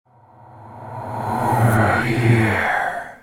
over_here3.ogg